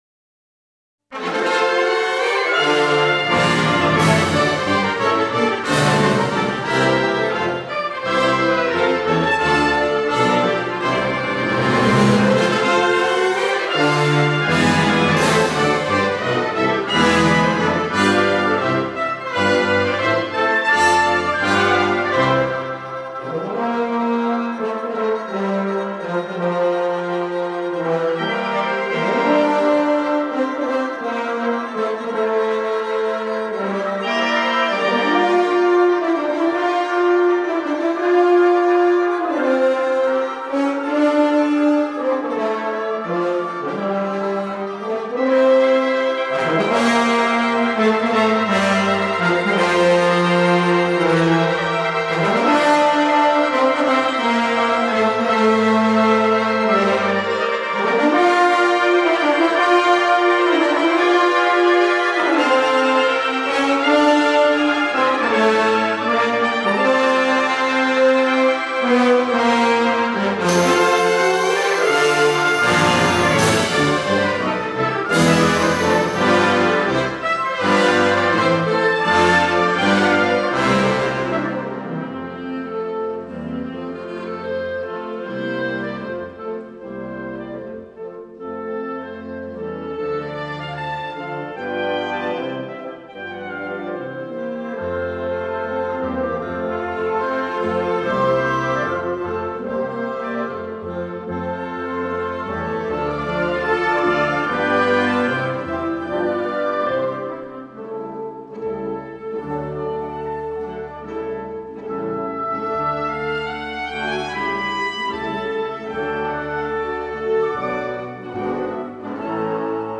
Gattung: Prelude
Besetzung: Blasorchester